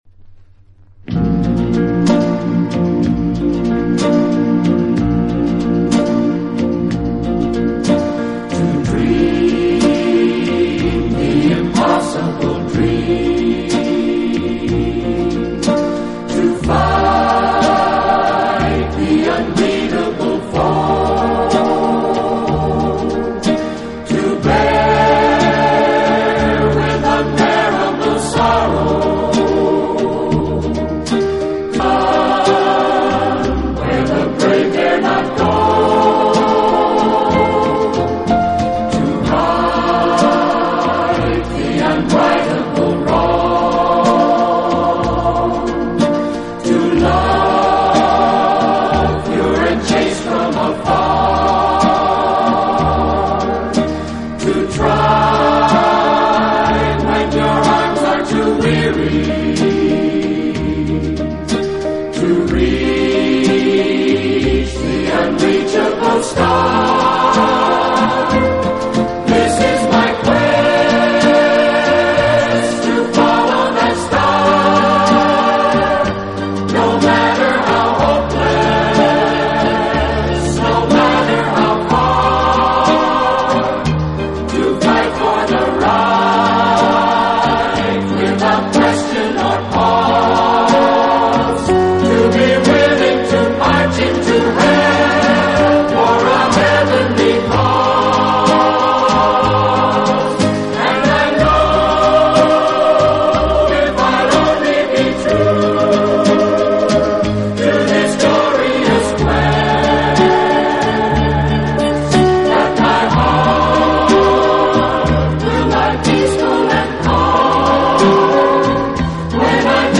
【轻音乐专辑】
他在60年代以男女混声的轻快合唱，配上轻松的乐队伴奏，翻唱了无数热